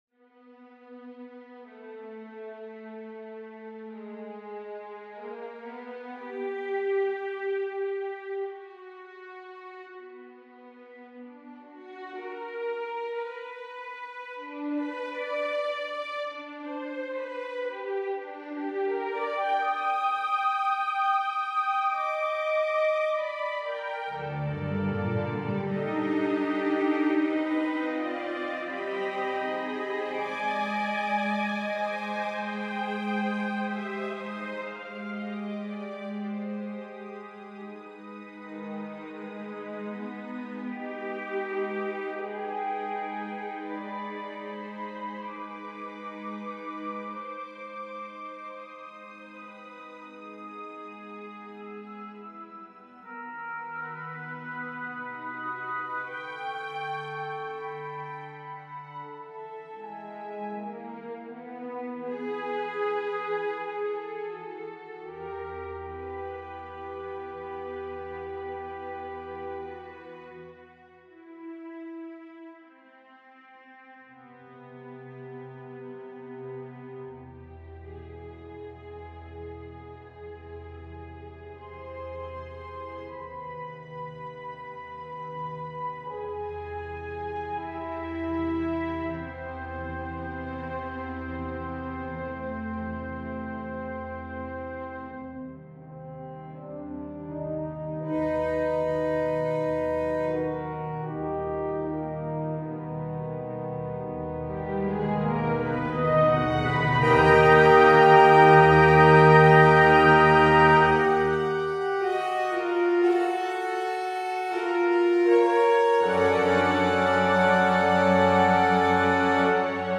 for orchestra
Orchestra: 2222; 4220; timp, 1 perc; strings
I should not have been surprised.